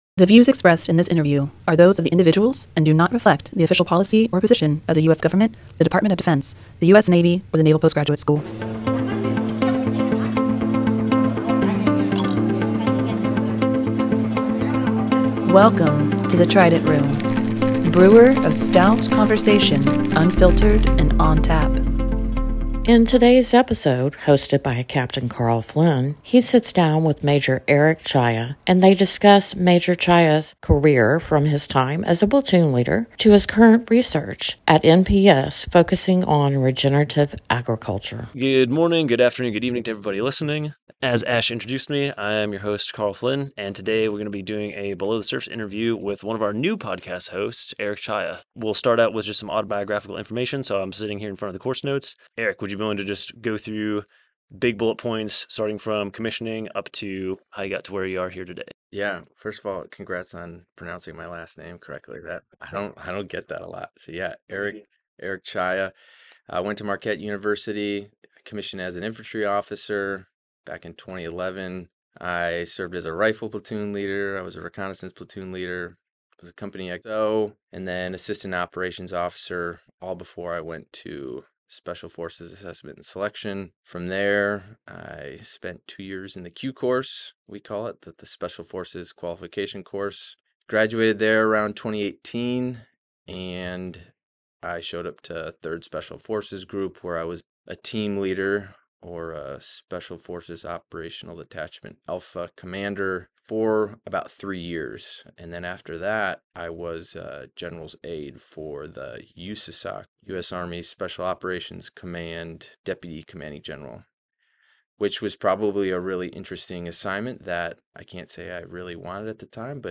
The views expressed in this interview are those of the individuals and do not reflect the official policy or position of the U.S. Government, the Department of Defense, the US Navy, or the Naval Postgraduate School.